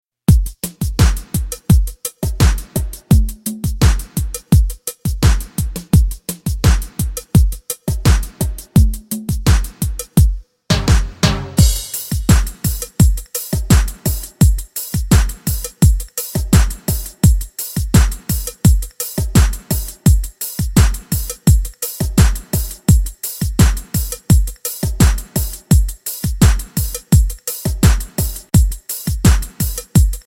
MP3 Demo Instrumental Version